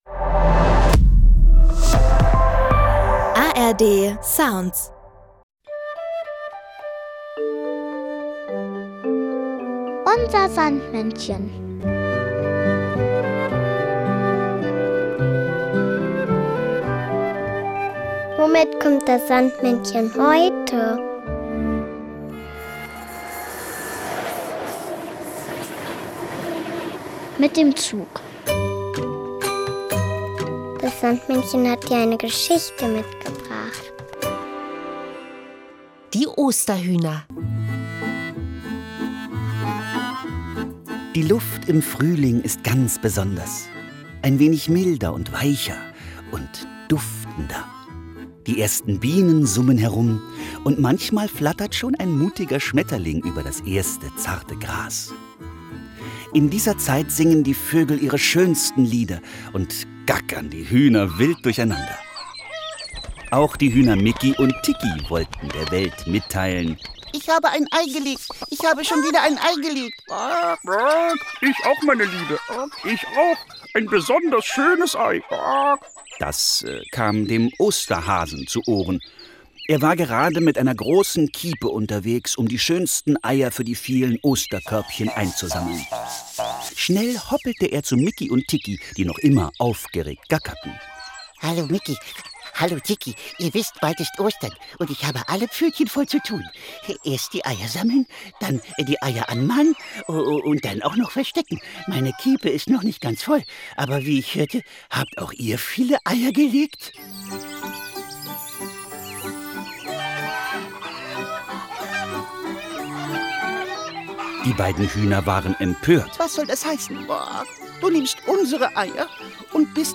Märchen: Die Osterhühner Mikki und Tikki